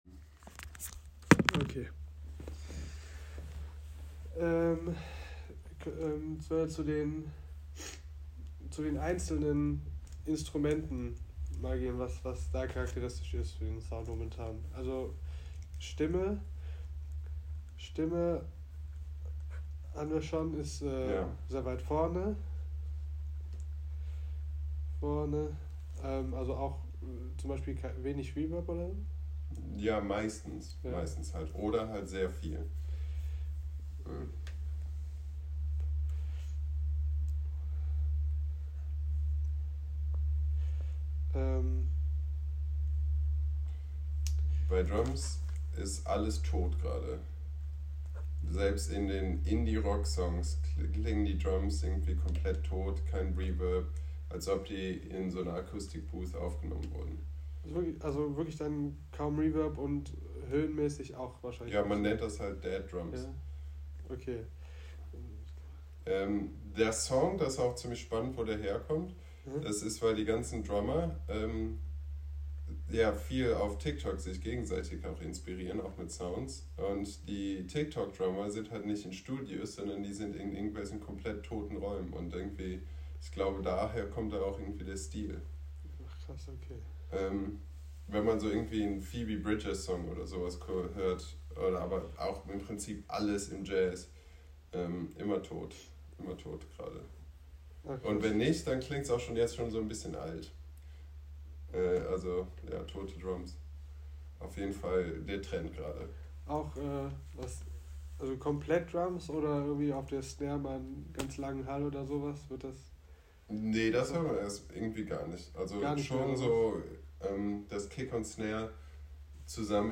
In this section you can find the expert interviews which I used to analyze the songs.